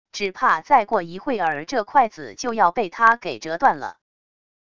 只怕再过一会儿这筷子就要被他给折断了wav音频